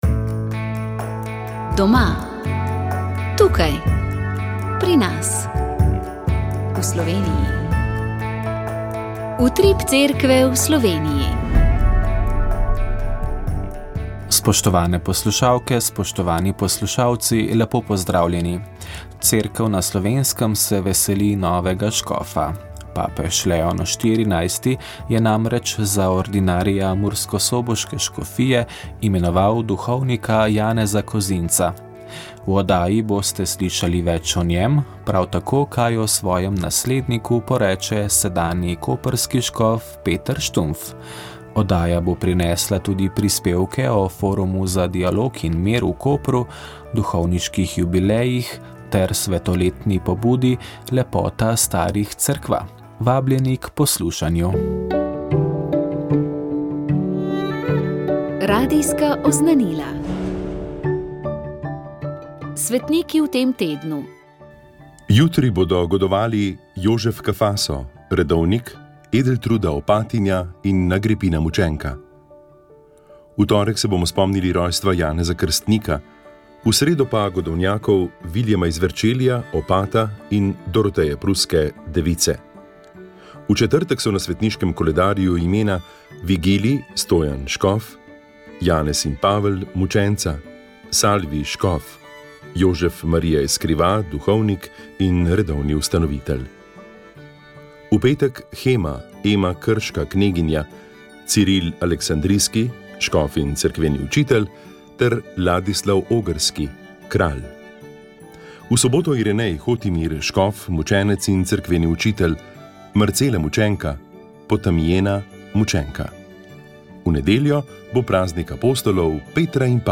Radijska kateheza